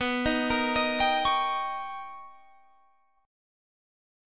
B+M9 chord